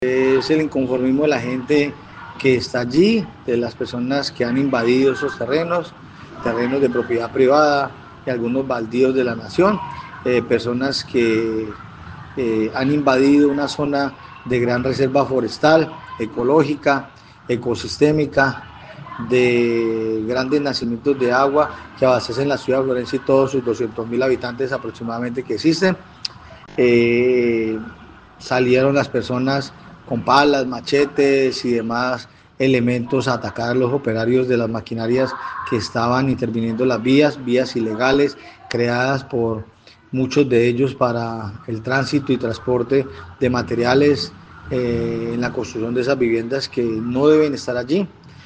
Carlos Mora, secretario de gobierno del municipio, dijo que, en el lugar existen ´mafias´ que utilizan a los humildes para deforestar zonas de protección ambiental, importantes para la ciudad, como esta, donde se surte de agua la capital caqueteña.